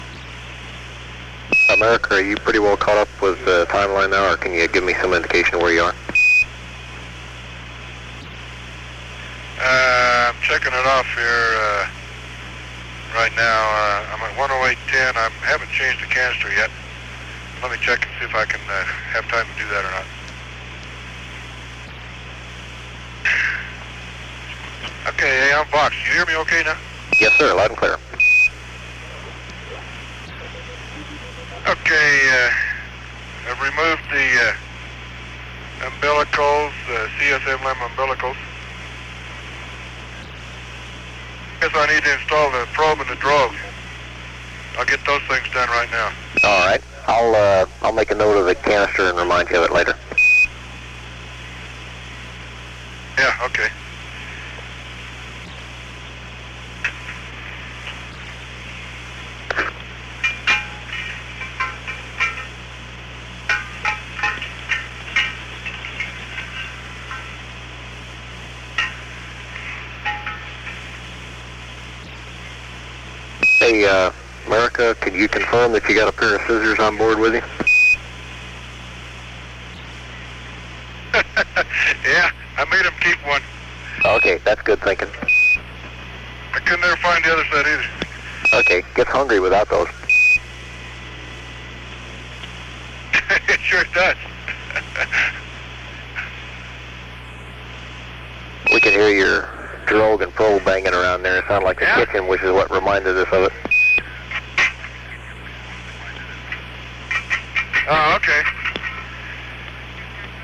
It appears that long quiet periods have been deleted, probably by a process of copying from one tape machine to another.